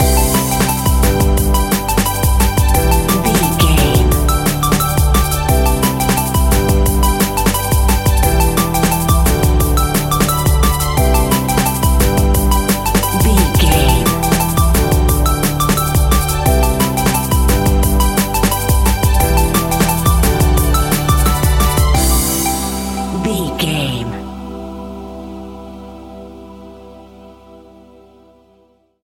Ionian/Major
Fast
groovy
uplifting
lively
bouncy
futuristic
electronic
sub bass
synth drums
synth leads
synth bass